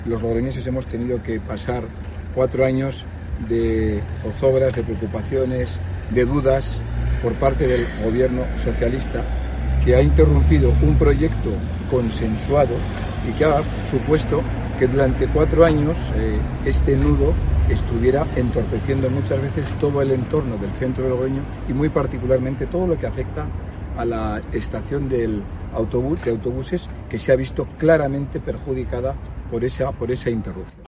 Ha sido junto al monumento a las víctimas del terrorismo, en el paseo del Espolón.
Posteriormente, el alcalde de Logroño, Conrado Escobar, ha dado lectura a un manifiesto que se ha leído hoy en numerosos puntos de España.